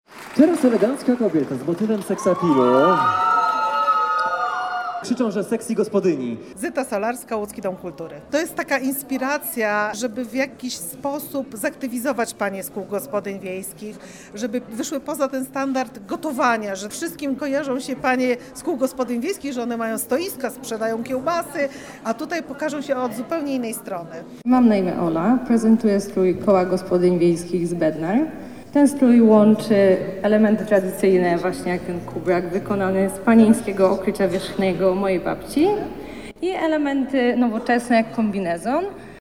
Wyjątkowy pokaz mody w Łowiczu
Lowicz-pokaz-mody.mp3